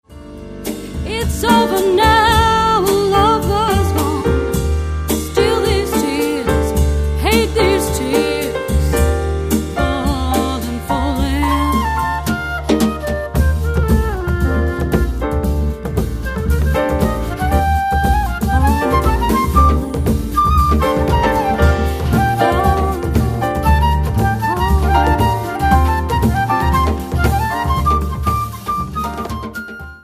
Australian, Jazz, Vocal